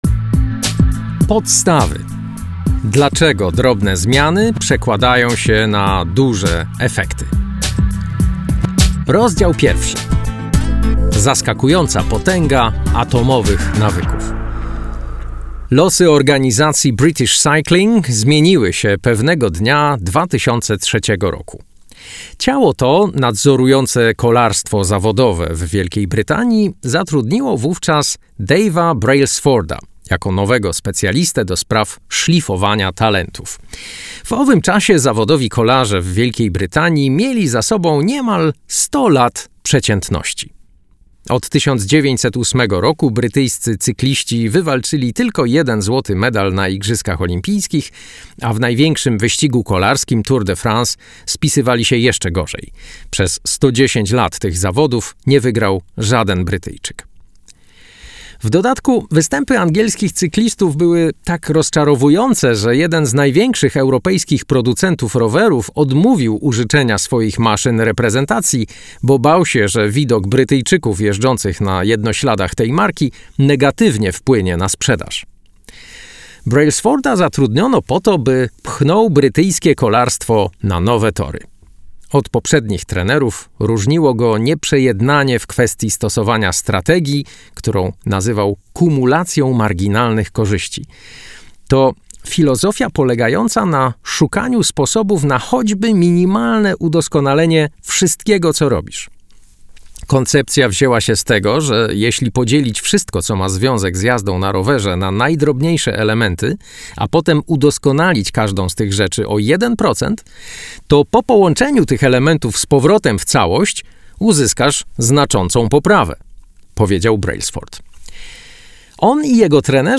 fragment książki: